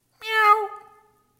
meows-3.mp3